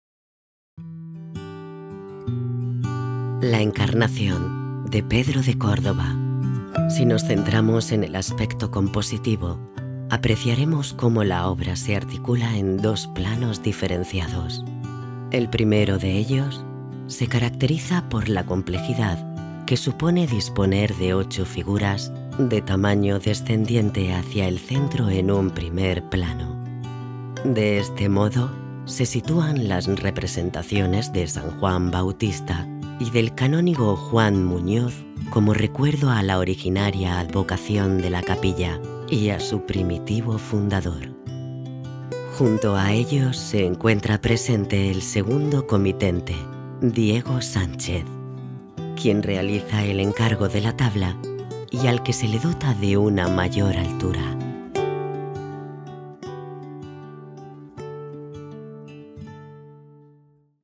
Género: Femenino
ELearning